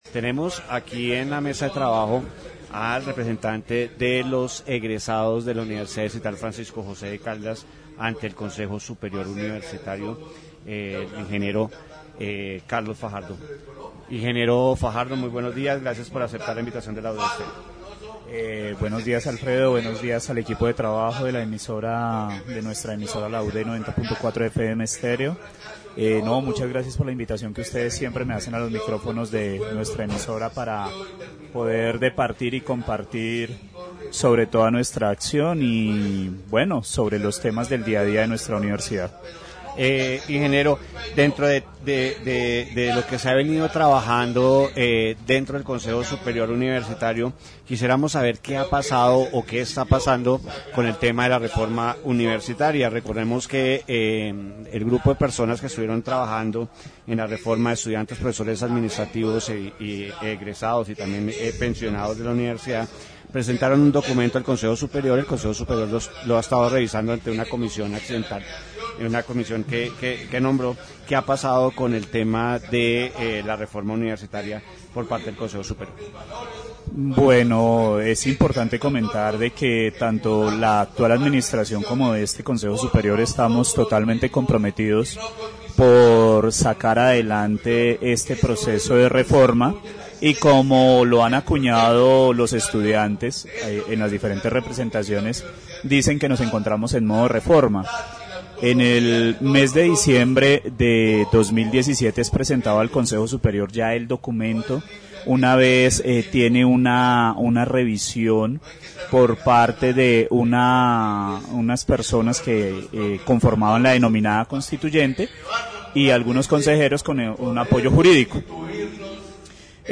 The interview also addresses the financial viability of the reform, noting that the Planning Advisory Office is currently conducting cost and transition pro